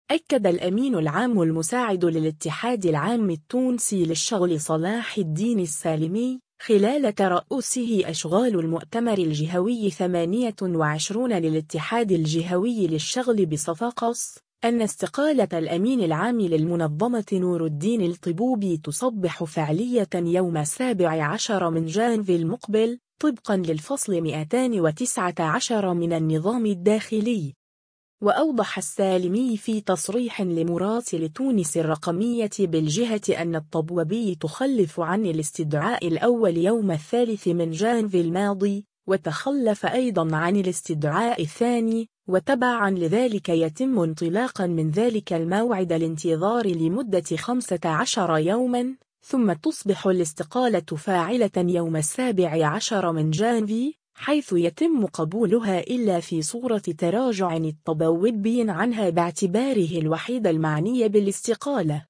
خلال ترؤسه أشغال المؤتمر الجهوي 28 للاتحاد الجهوي للشغل بصفاقس